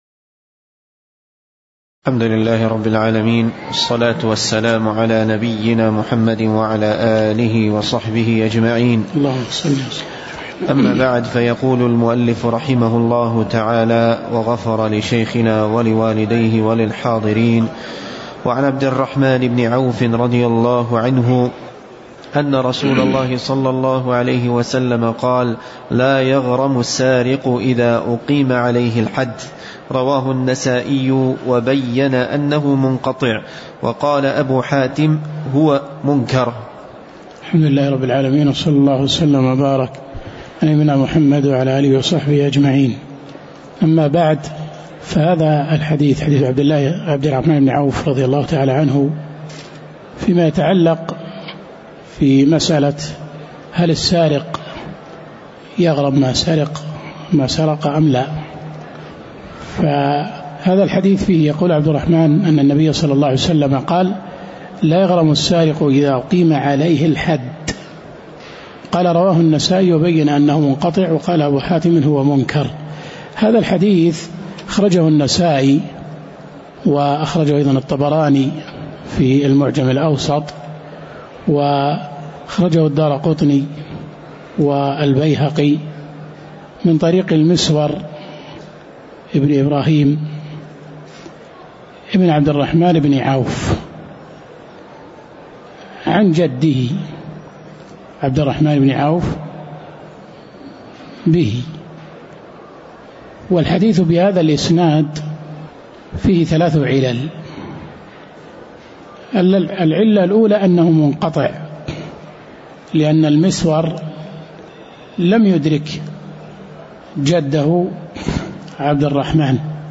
تاريخ النشر ٢٩ محرم ١٤٤٠ هـ المكان: المسجد النبوي الشيخ